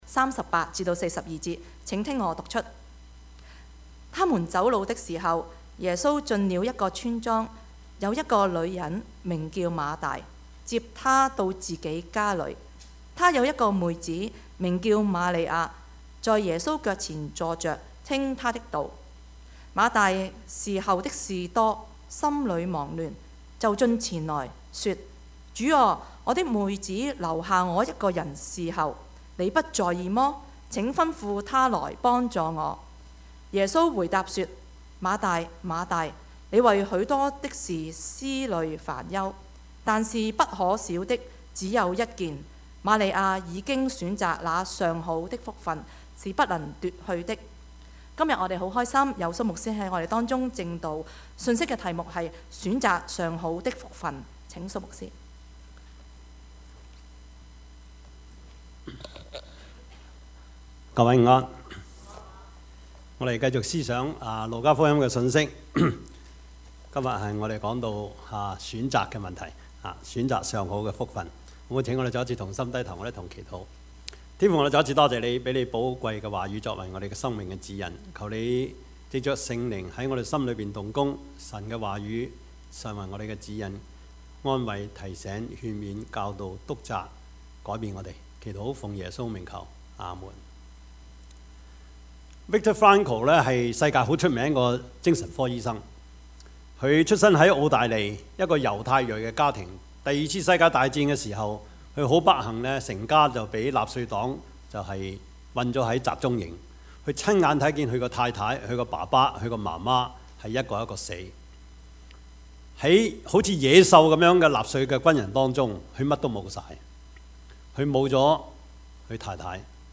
Service Type: 主日崇拜
Topics: 主日證道 « 天生你才必有用 未知生、焉知死？